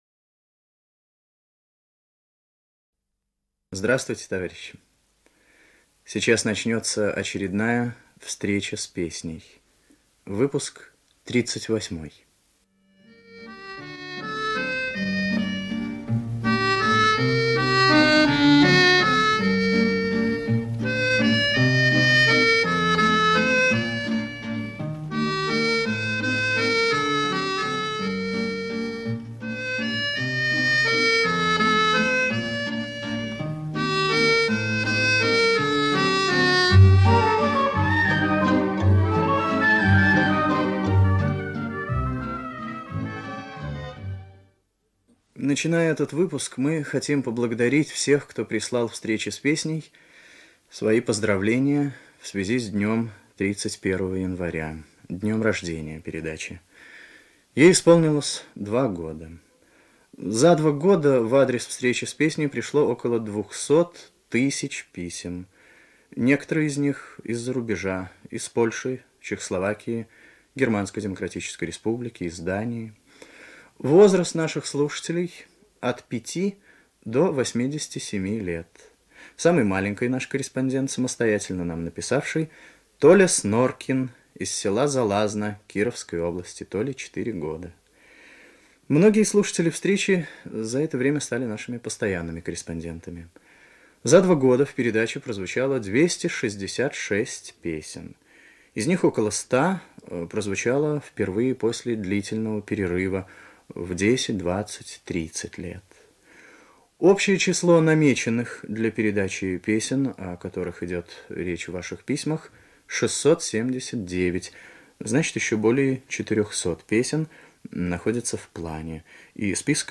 1. Заставка. Исполняет оркестр;